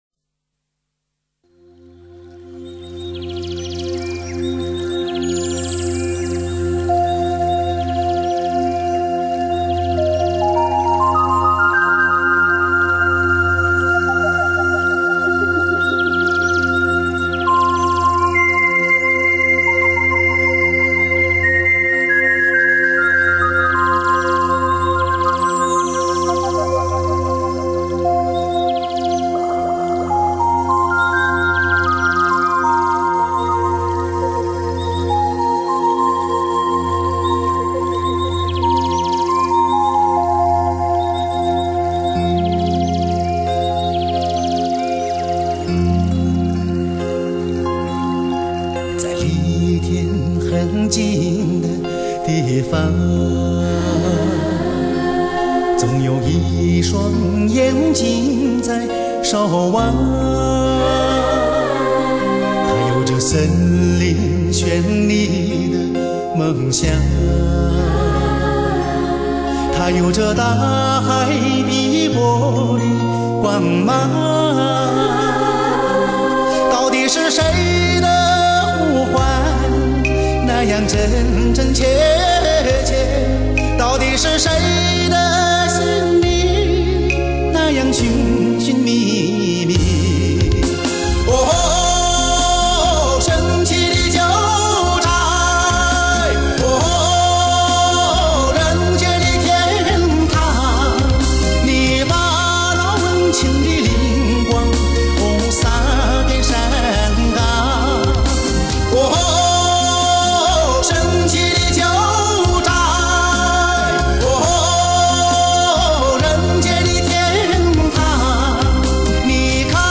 纯净雪域，一尘不染 瞬间的感动，心灵的净土，梦幻的天籁之音